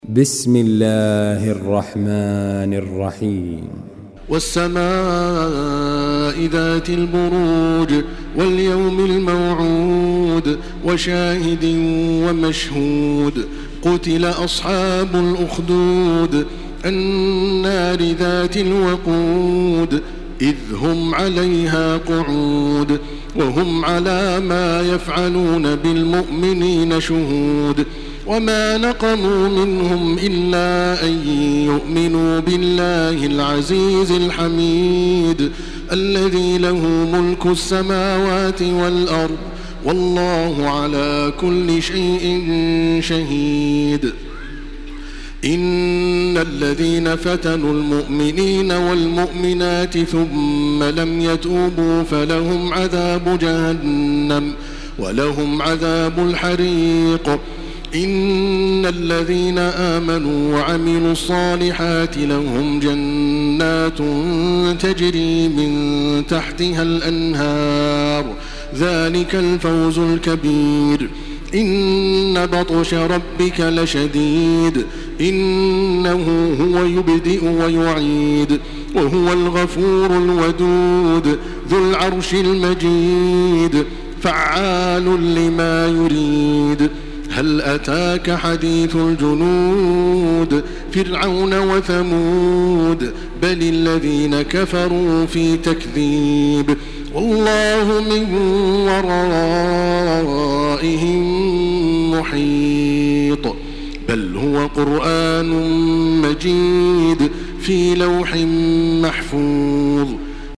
Makkah Taraweeh 1429
Murattal